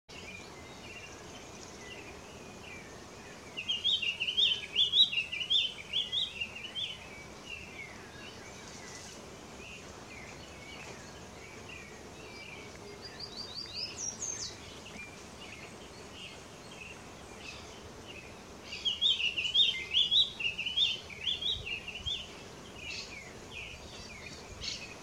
Frutero Azul (Stephanophorus diadematus)
Nombre en inglés: Diademed Tanager
Localidad o área protegida: Reserva Privada El Potrero de San Lorenzo, Gualeguaychú
Condición: Silvestre
Certeza: Observada, Vocalización Grabada
Frutero-Azul.mp3